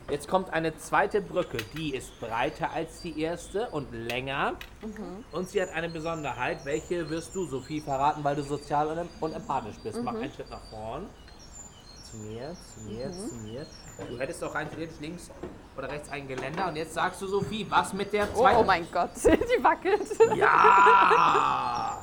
Blinde und sehbehinderte Guides begleiten Besuchende bei Dialog im Dunkeln durch lichtlose Räume.
DialogImDunkeln_Tour_mixdown_Haengebruecke.mp3